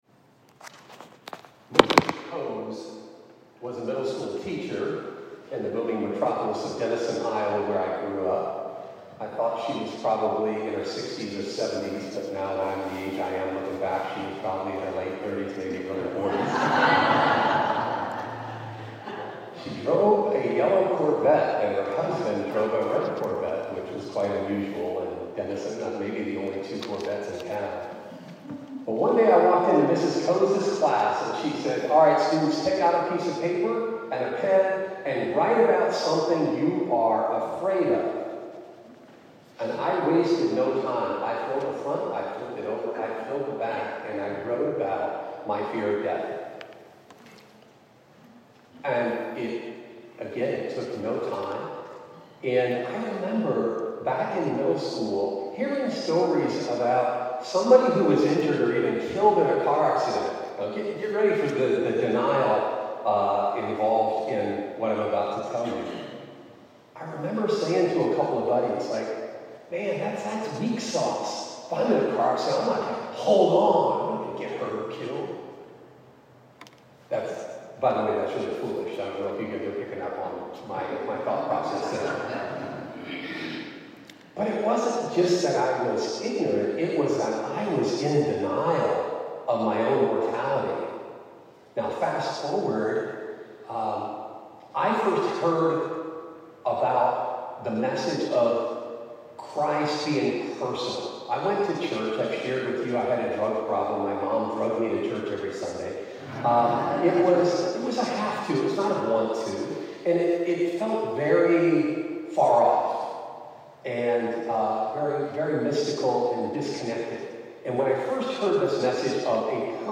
Sermon - Love Evicts Fear